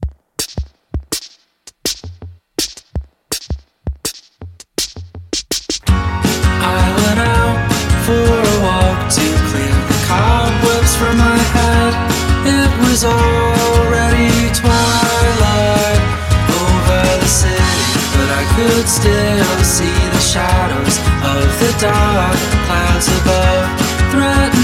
scorre morbido e piuttosto solare
voce di velluto
si muove sul filo del barocco e dell'autunno